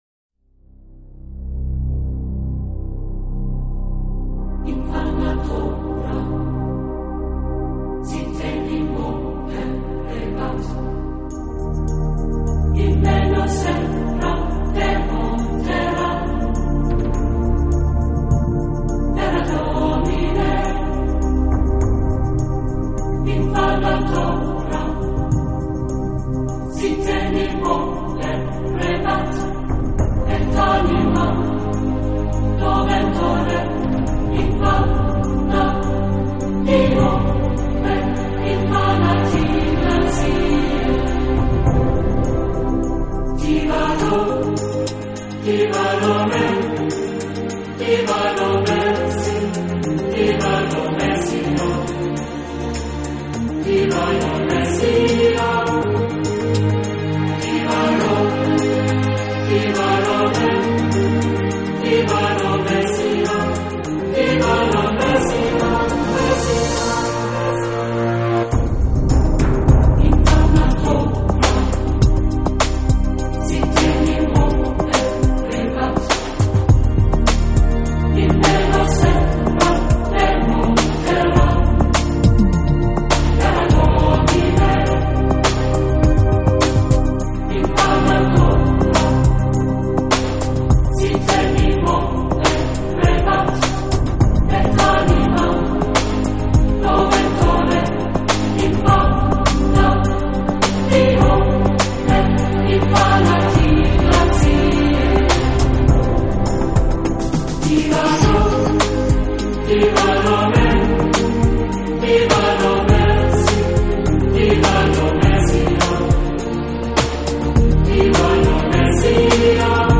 是领唱上，都加重了女声的成分，因而整体风格都显得更柔和、更温暖。
强，很易上口，感觉上更流行化一些吧。